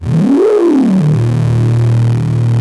OSCAR 10 D#1.wav